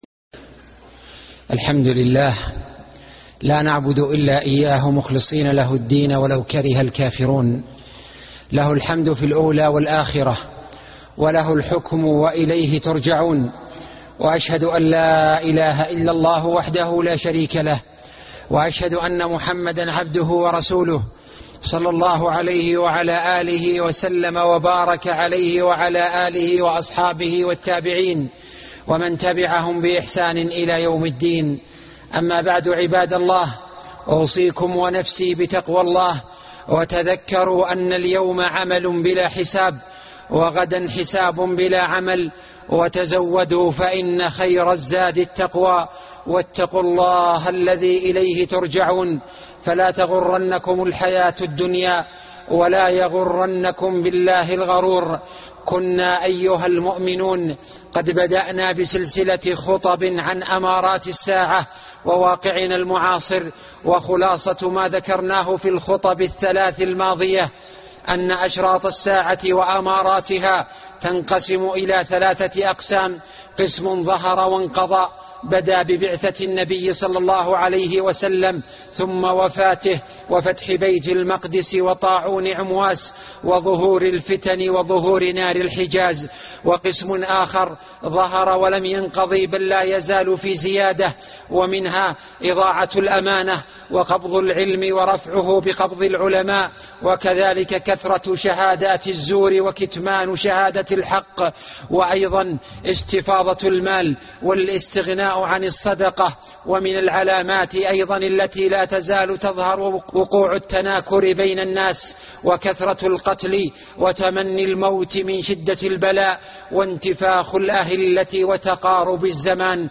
أشراط الساعة (4)خطب الجمعة